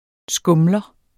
Udtale [ ˈsgɔmlʌ ]